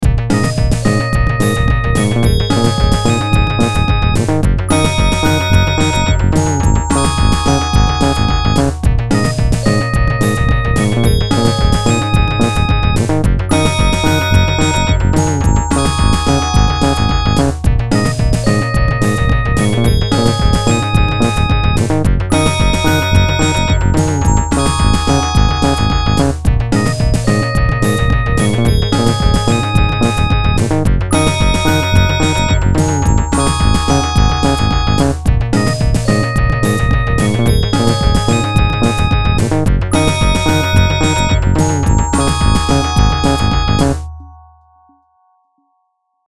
Filed under: Instrumental | Comments (1)